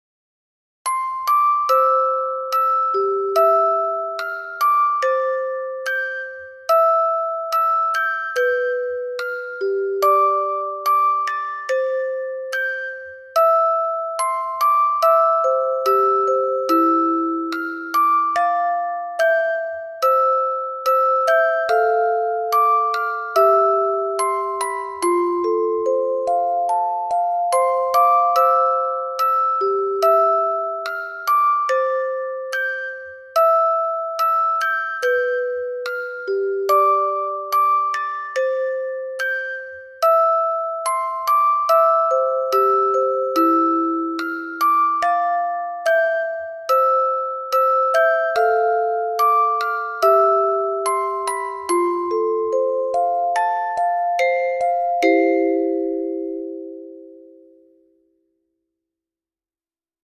スカイメール（！）で送って頂いたメロディーをオルゴールにしてみました。
心安らぐメロディーです。 リテイクにあたって、少しアレンジを追加してあります。